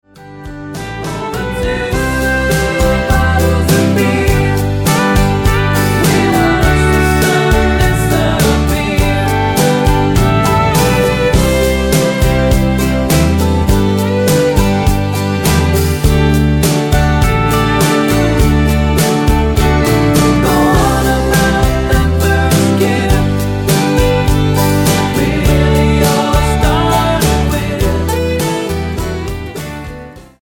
--> MP3 Demo abspielen...
Tonart:G mit Chor